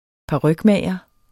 Udtale [ -ˌmæˀjʌ ]